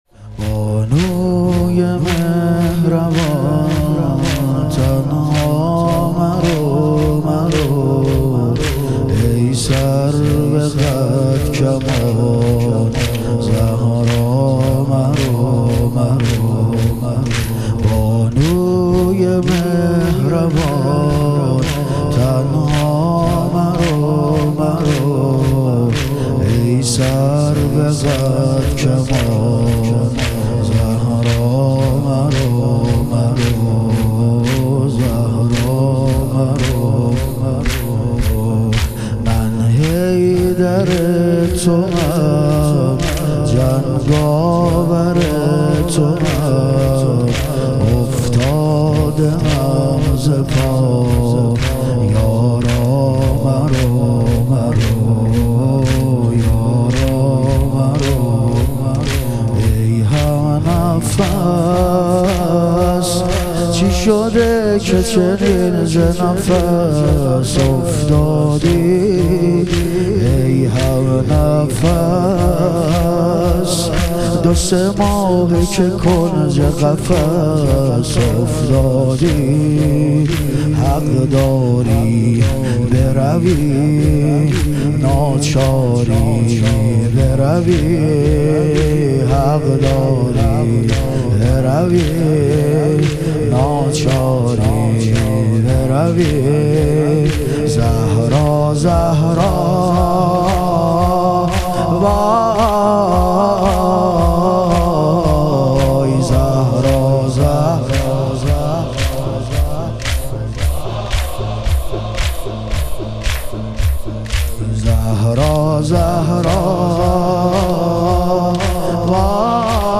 ایام فاطمیه دوم - تک